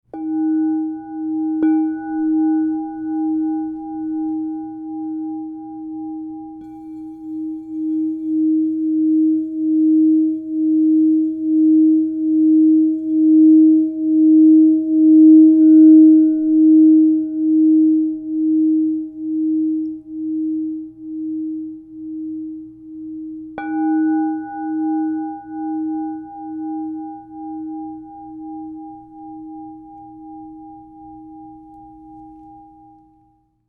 Grandmother Supreme 8″ D# +15 Crystal Tones singing bowl
The 8-inch size delivers focused, harmonious tones, making it ideal for meditation, sound therapy, and sacred rituals.
Enhance your journey with 8″ Crystal Tones® alchemy singing bowl made with Grandmother Supreme in the key of D# +15.
528Hz (+)